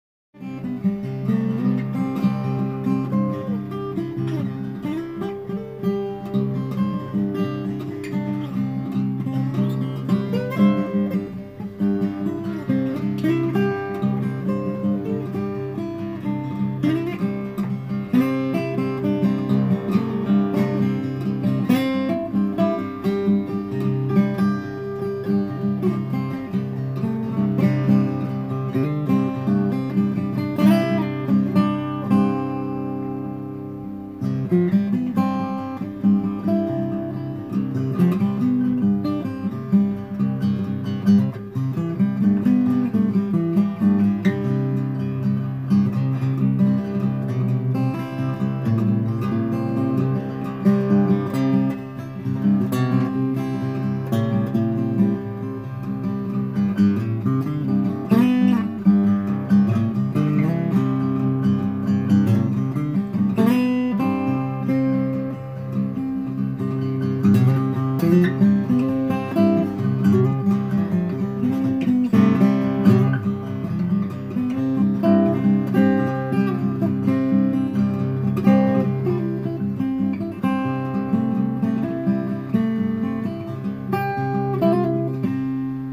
Note: This is simply a sample phone recording of "Mariah" and as such does not accurately represent the full range and beautiful sound of the guitar!